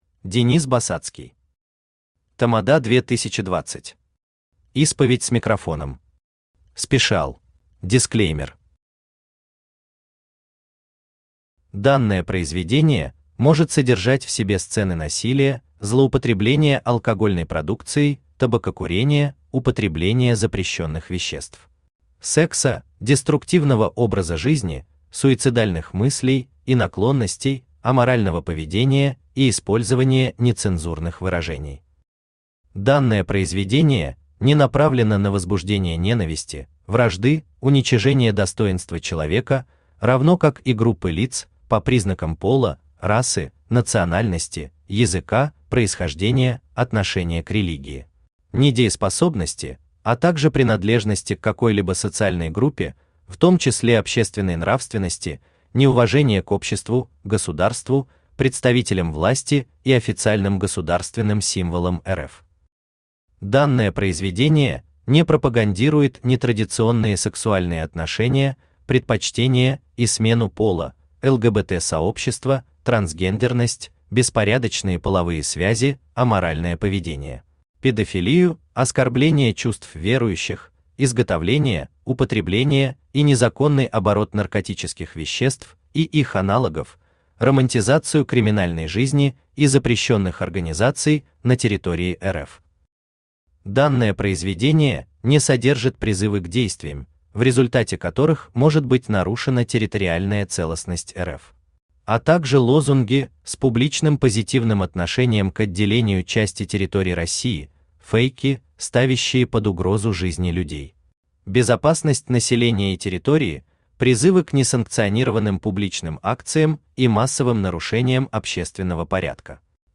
Аудиокнига Тамада 2020. Исповедь с микрофоном. «Спешиал» | Библиотека аудиокниг
«Спешиал» Автор Денис Басацкий Читает аудиокнигу Авточтец ЛитРес.